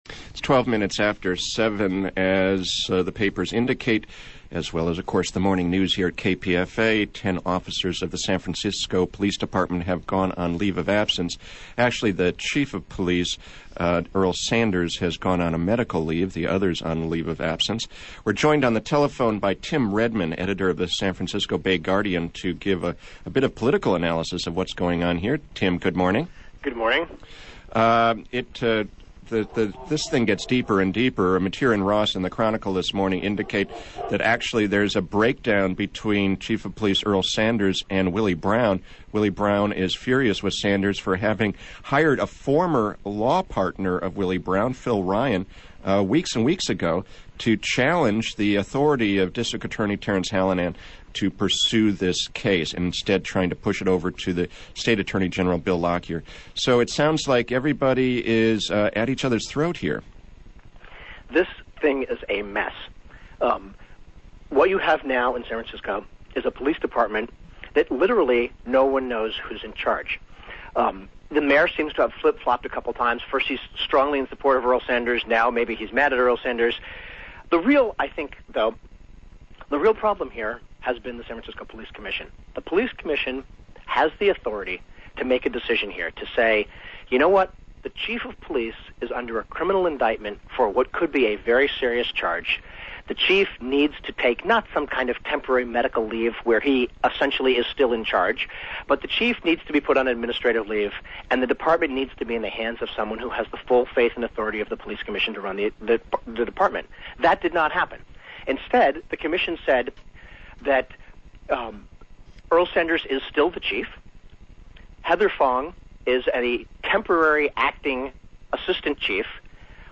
was interviewed on KPFA's Morning Show, March 4